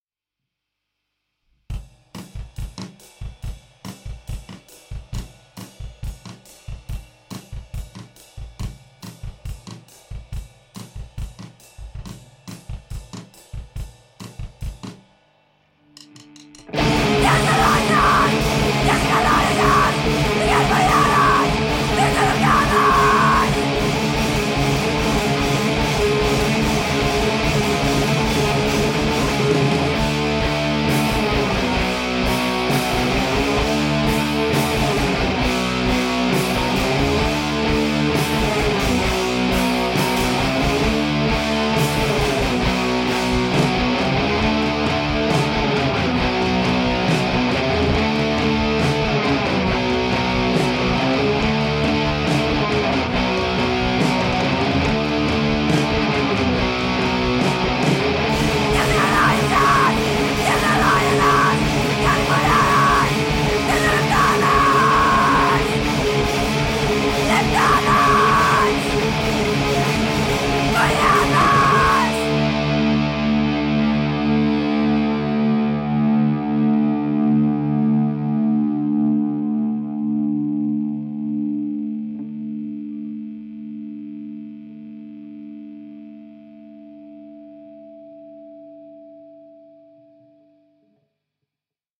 DIY Hardcore Punk band from Ioannina, Greece
κιθάρα-φωνητικά
drums-φωνητικά